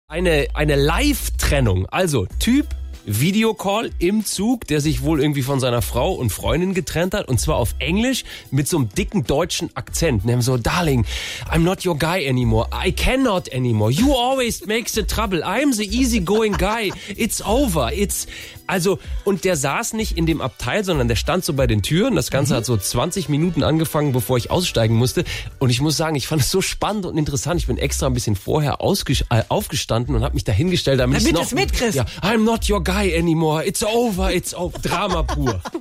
Ein Mitfahrer nutzt die Fahrzeit für einen Videocall mit seiner (noch) Freundin/Ehefrau und sagt mit deutschem Akzent: